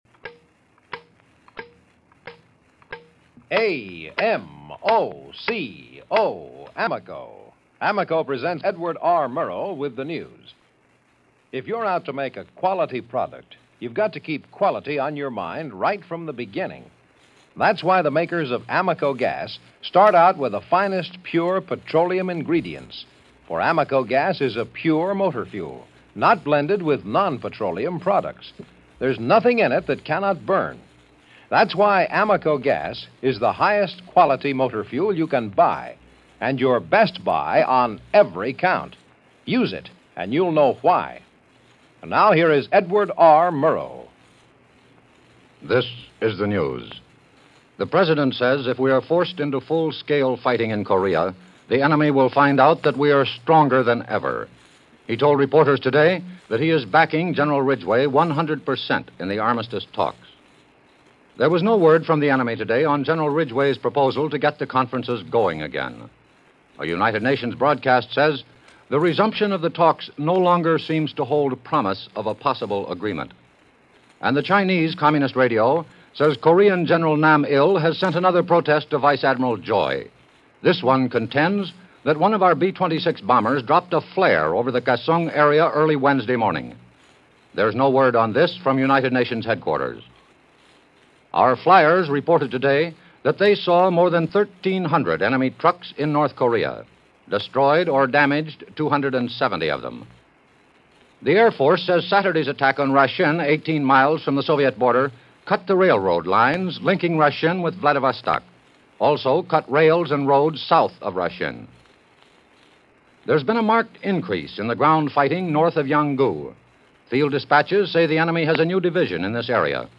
Back In Korea - Foreign Aid - Decaying Integrity - August 30, 1951 - Edward R. Murrow And The News - CBS Radio.